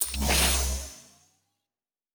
pgs/Assets/Audio/Sci-Fi Sounds/Doors and Portals/Door 2 Open.wav at master
Door 2 Open.wav